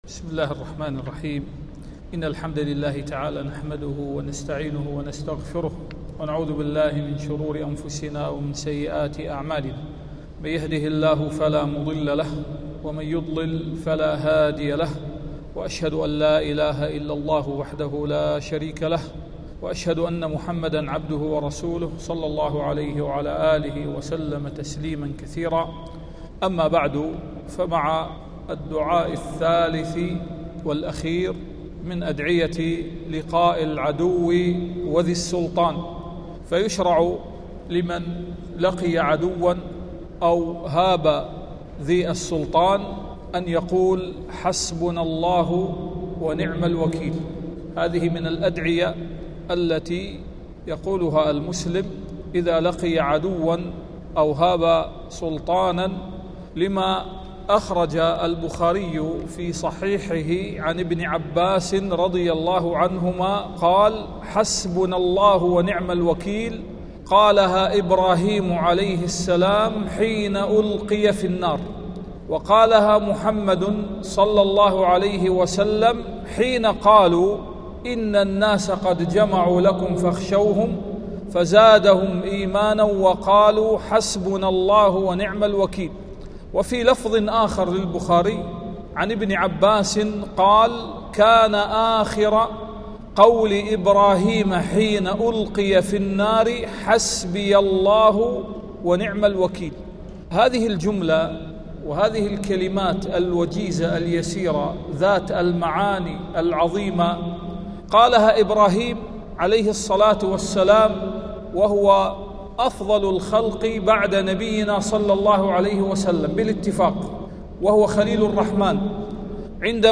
سلسلة دروس في معرفة معاني الأذكار والفوائد العملية والإيمانية منها ومناسباتها وفضلها.